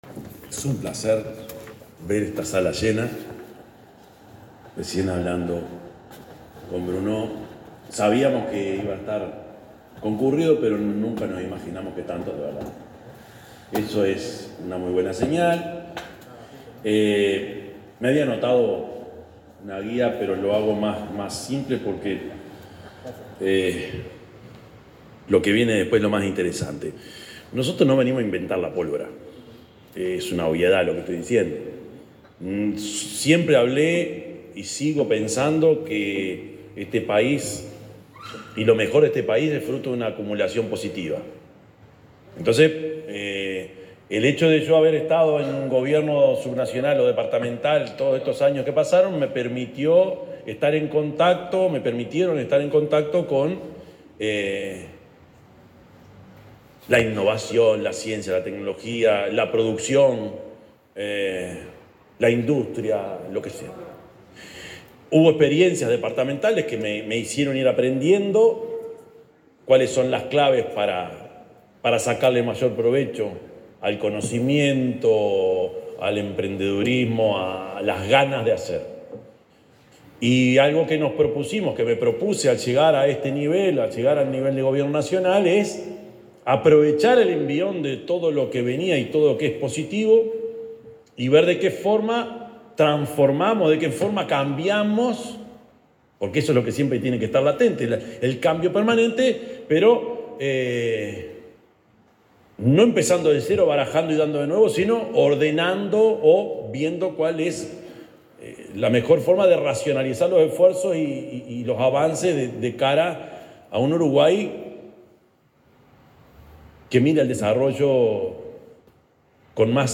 Palabras del presidente de la República, Yamandú Orsi
El presidente de la República, profesor Yamandú Orsi, participó en el lanzamiento del programa Uruguay Innova.